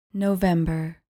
Pronounced: no-VEM-ber